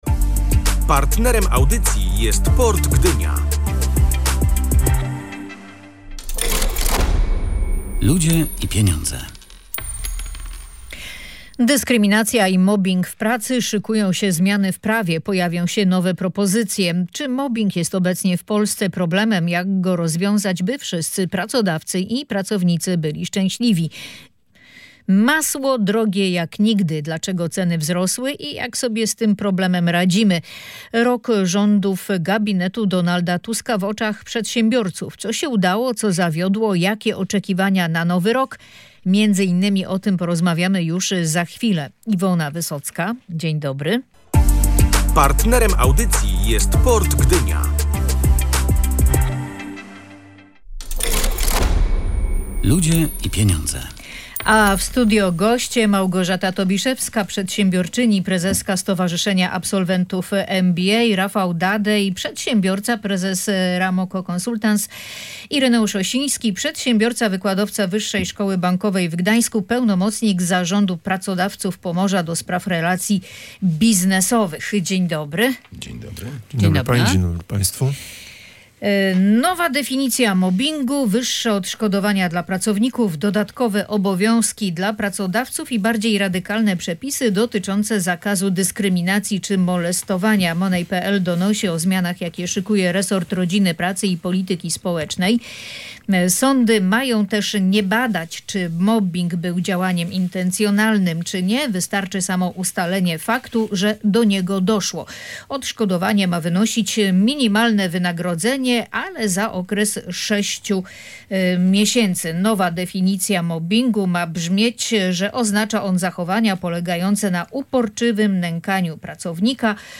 Resort Rodziny Pracy i Polityki Społecznej szykuje nowe, zaostrzone przepisy antymobbingowe. Zmieni się między innymi definicja mobbingu. Między innymi o tym w audycji „Ludzie i Pieniądze” dyskutowali goście